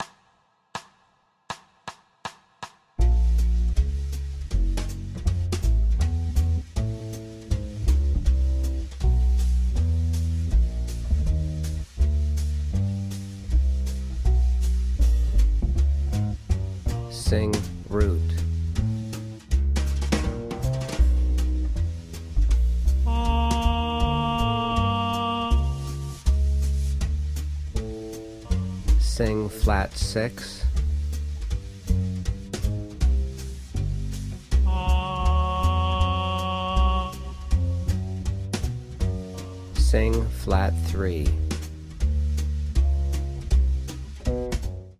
• Bass Only Singing Tenor